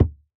Minecraft Version Minecraft Version 1.21.5 Latest Release | Latest Snapshot 1.21.5 / assets / minecraft / sounds / block / packed_mud / step1.ogg Compare With Compare With Latest Release | Latest Snapshot